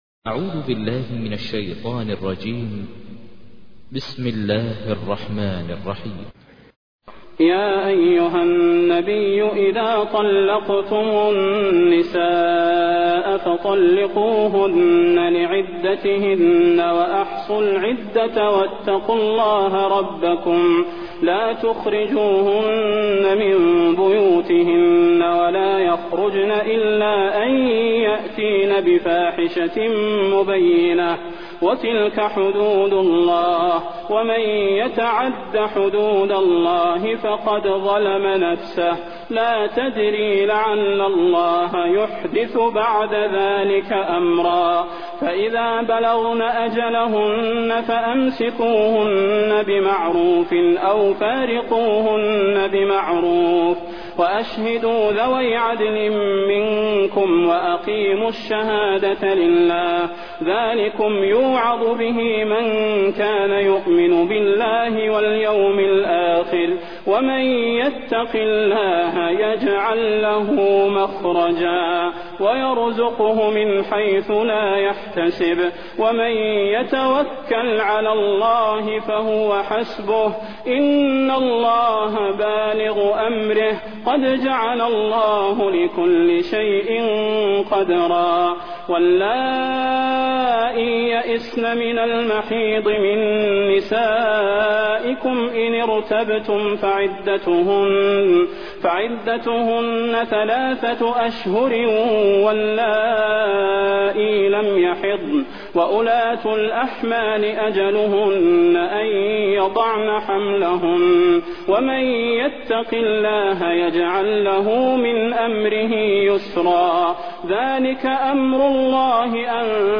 تحميل : 65. سورة الطلاق / القارئ ماهر المعيقلي / القرآن الكريم / موقع يا حسين